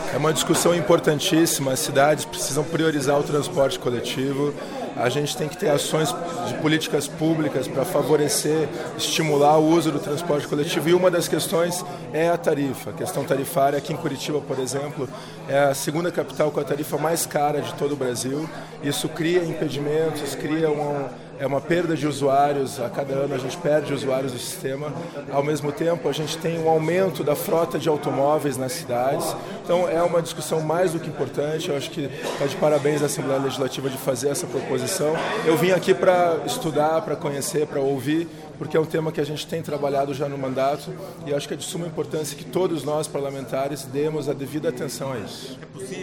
Ouça a entrevista do parlamentar.
(Sonora)